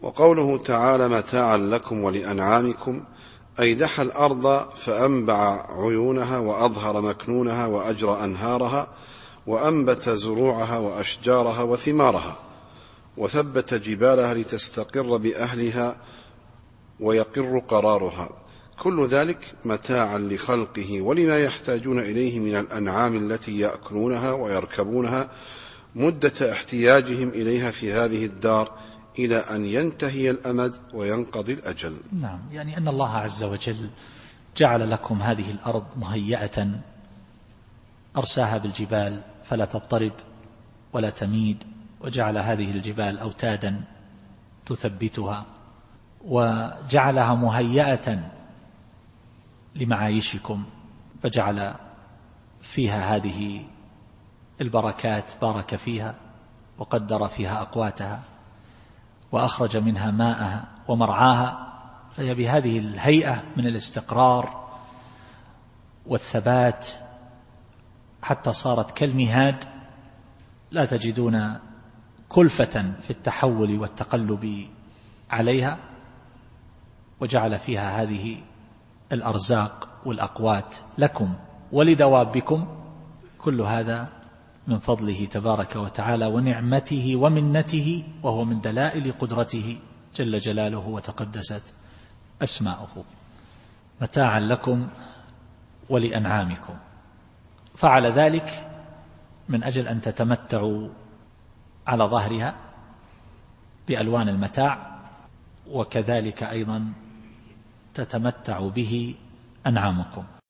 التفسير الصوتي [النازعات / 33]